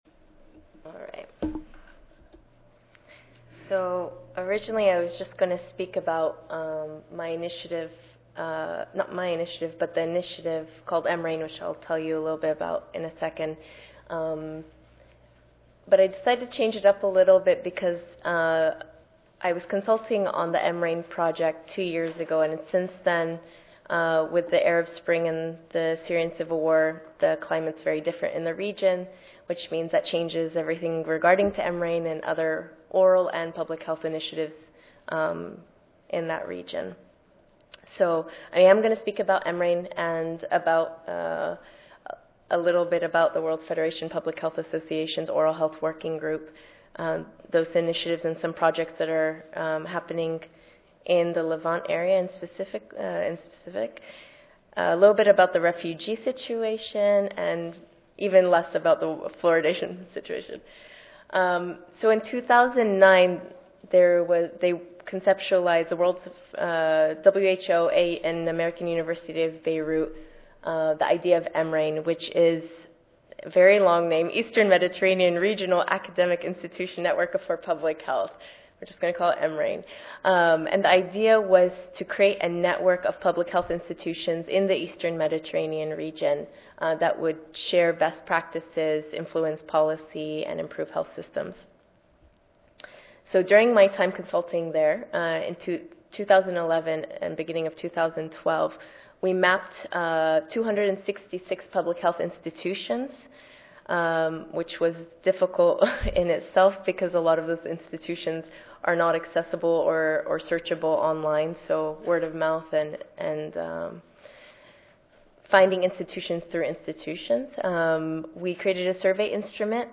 4242.0 New Initiatives for Better Global Oral Health Tuesday, November 5, 2013: 12:30 p.m. - 2:00 p.m. Oral The purpose of this panel is to present new initiatives for better global oral health initiated by the World Federation of Public Health Associations (WFPHA) and the Global Oral Health Interest Group of the Conference of Universities for Global Health. New initiatives in the Middle East and Indonesia will also be presented.